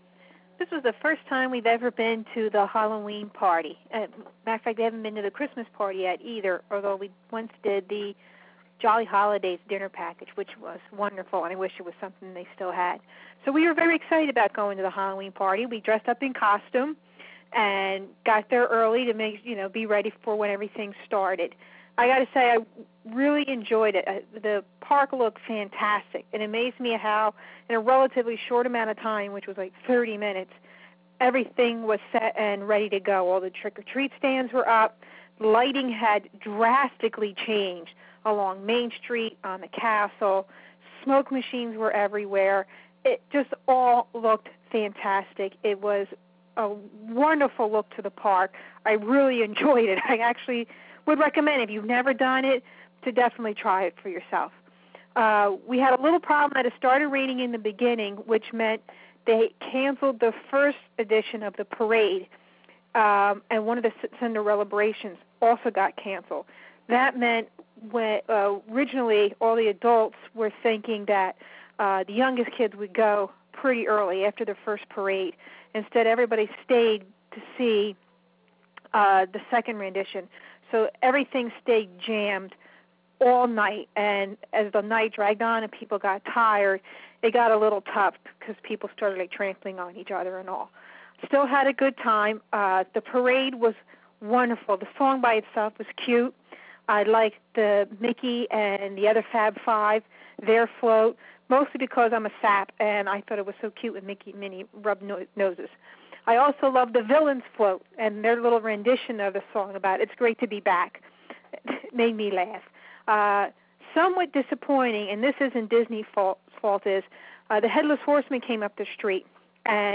What a shock, huh? big grin So I recorded it much, much later at home, picking WDW music from Sorcerer 1 for the background. Instead, you hear the dogs playing tug of war over a toy. ;)